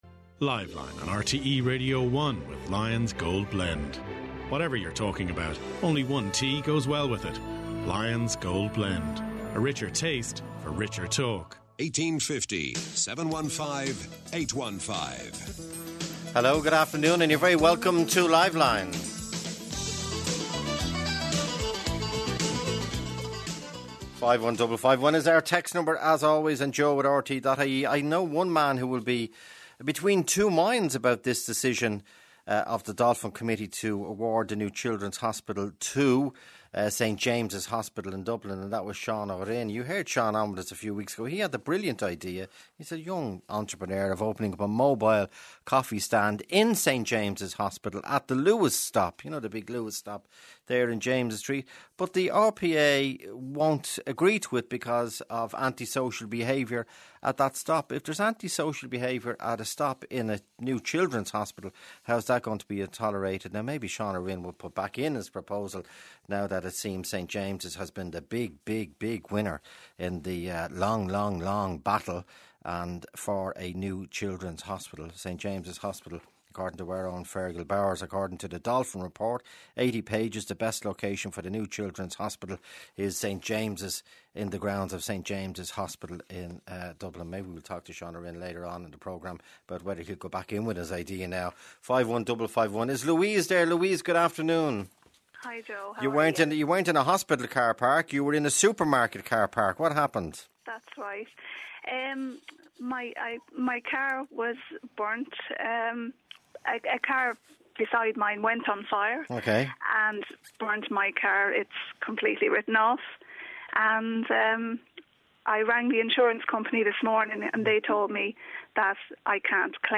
Was listening to Liveline today, I know :o, there was a woman on who had parked her car in a shopping centre and when she returned to her car it was burned beyond repair as a result of a car parked next to it going on fire.